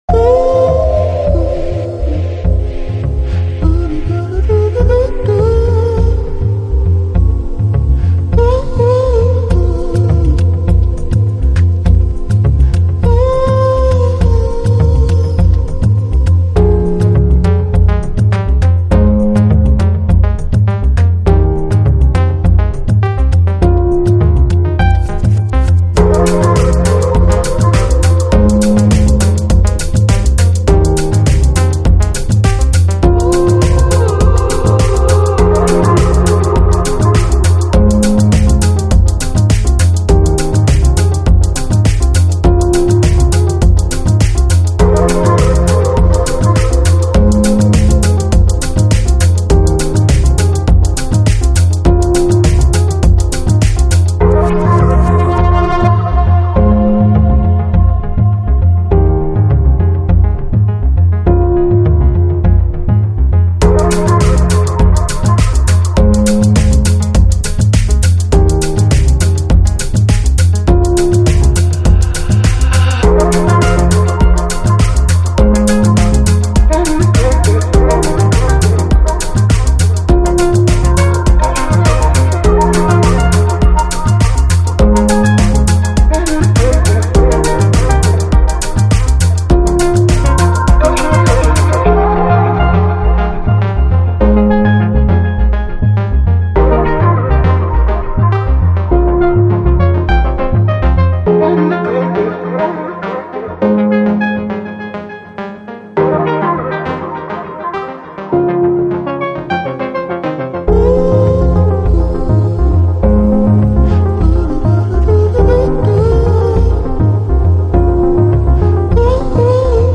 Жанр: Chillout